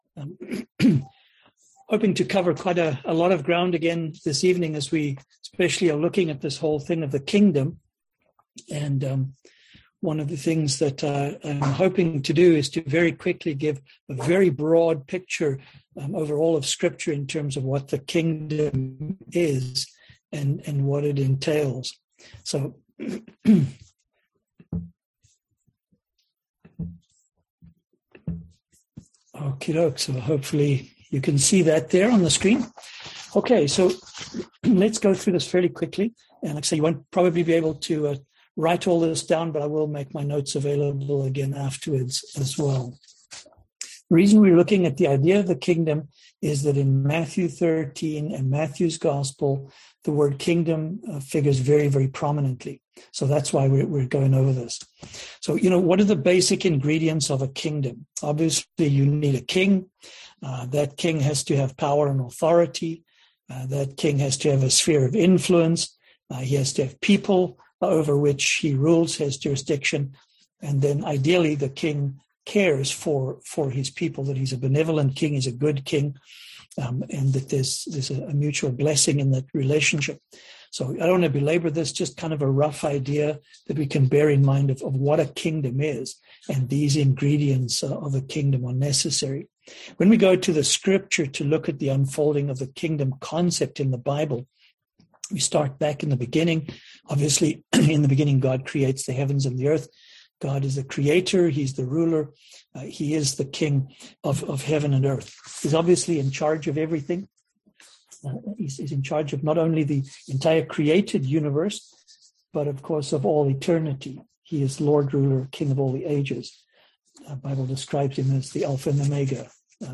Kingdom Parables 2021 Passage: Matthew 13 Service Type: Seminar